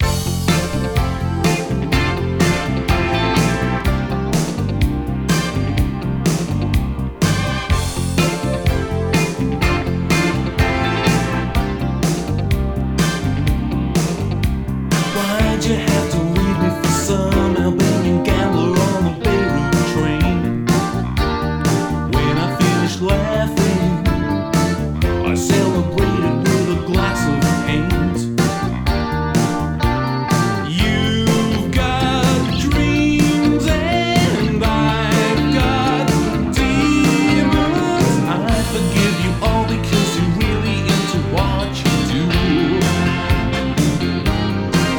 前作での路線を突き抜け、フレッシュで良質なポップスが鳴らされた傑作。魅力的なメロディが溢れ、アレンジも凝ってます。”
Rock, Pop, Indie　UK　12inchレコード　33rpm　Stereo